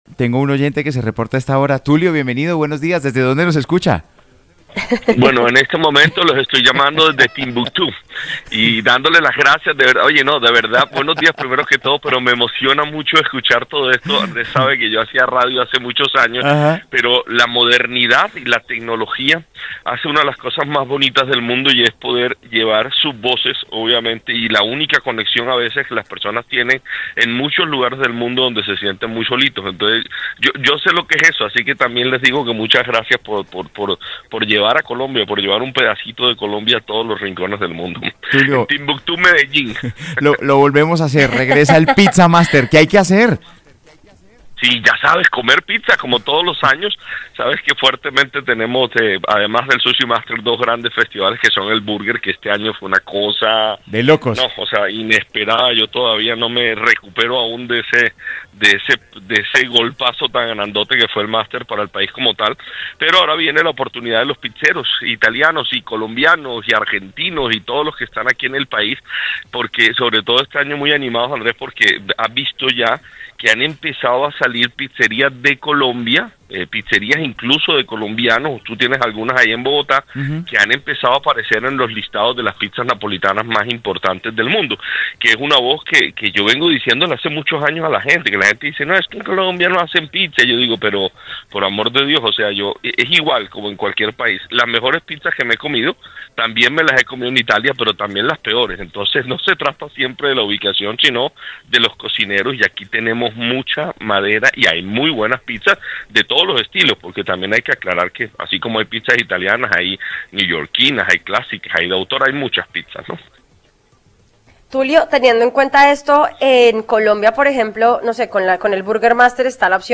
En entrevista con A vivir